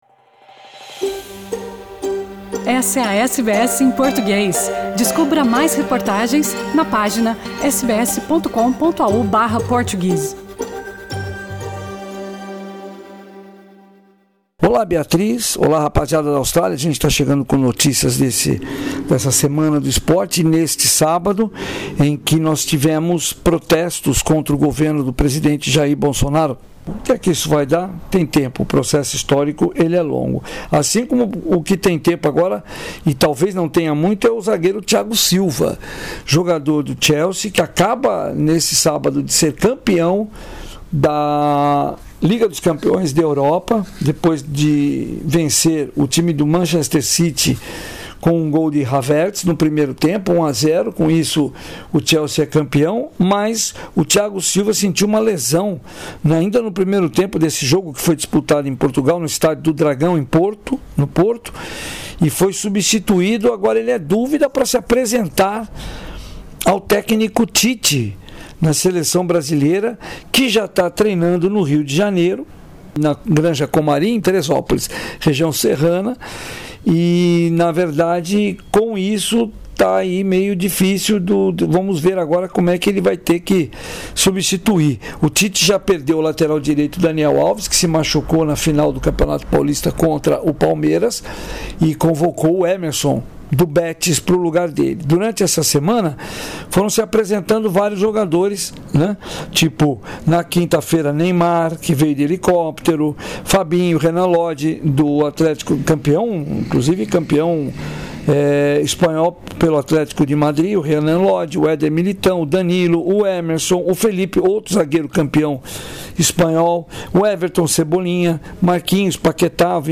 boletim esportivo